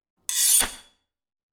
SWORD_21.wav